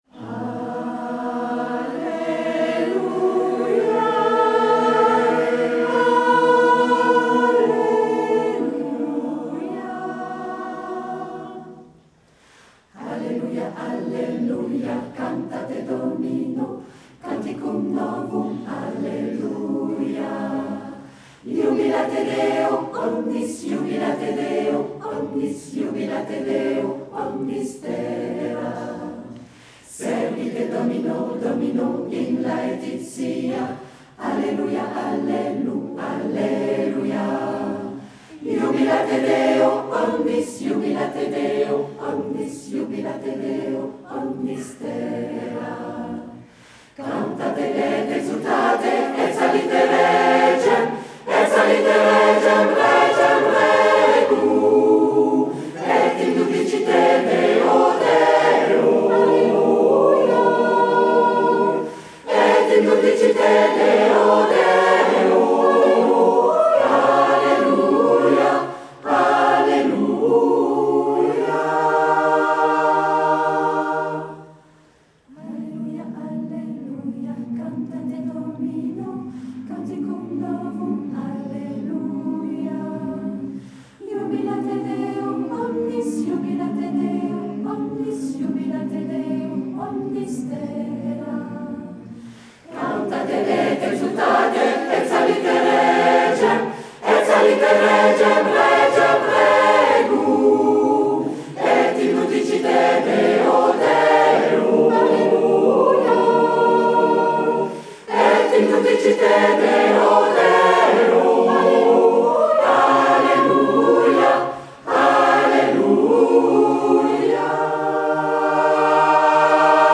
Cantate Domino Gland2023.mp3